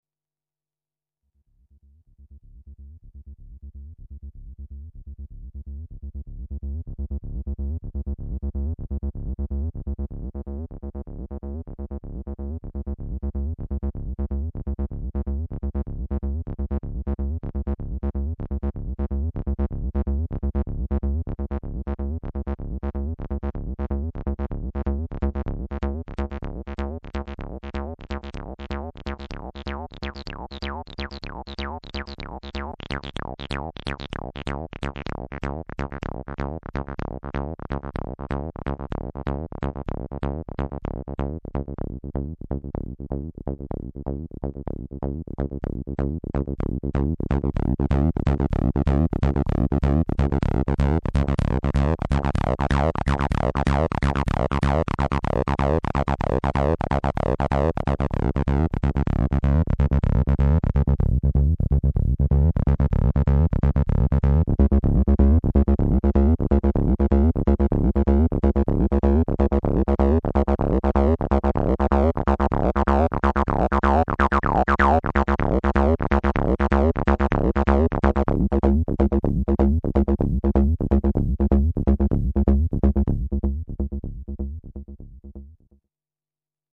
Sound Example 1 - A short loop